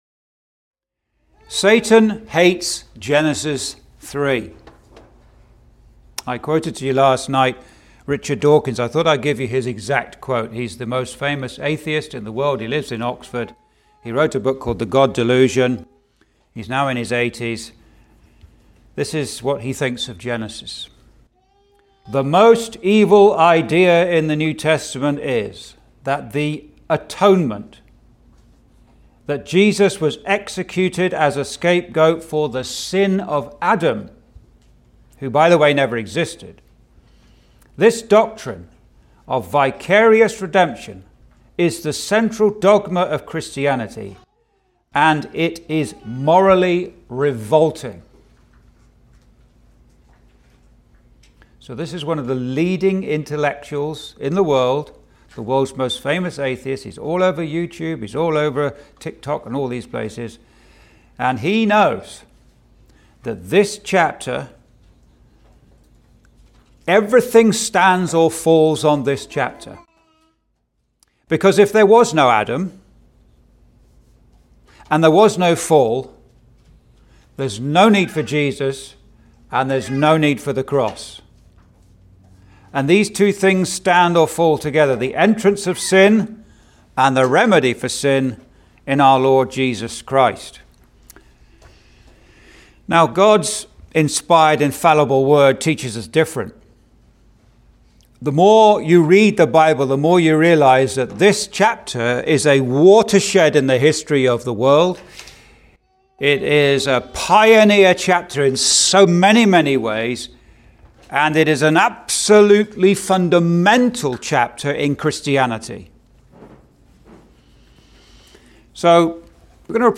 (Recorded in Thunder Bay Gospel Hall, ON, Canada on 10th Nov 2025) Complete